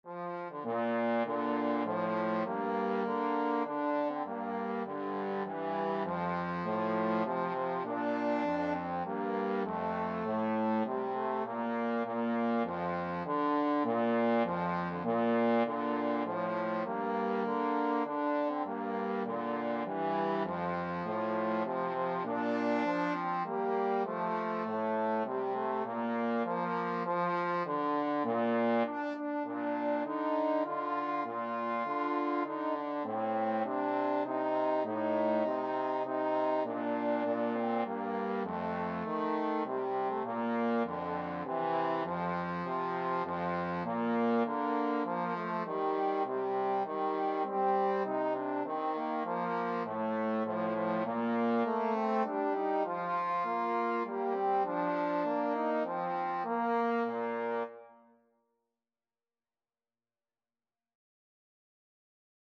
Trombone 1Trombone 2
3/4 (View more 3/4 Music)